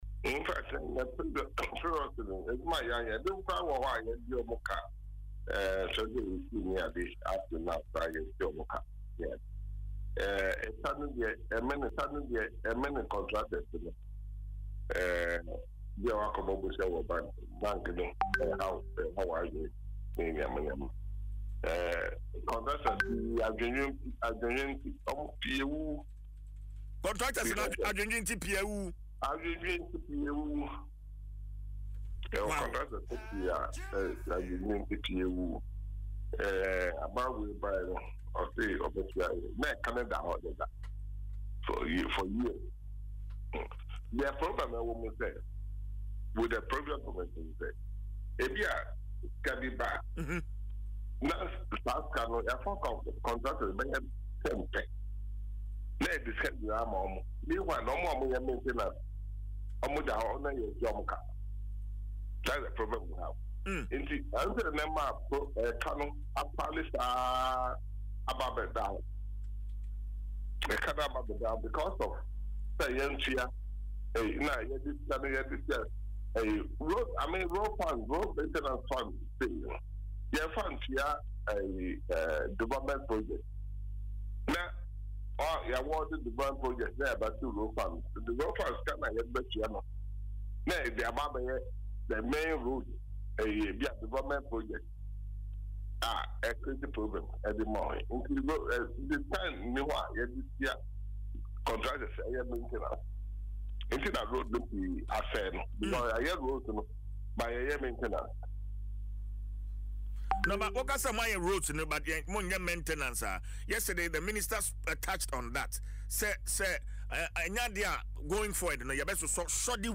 Hot AudioNewsLocal NewsTop Story